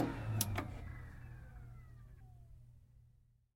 Завершение работы компьютера
zavershenie_raboti_kompyutera_91s.mp3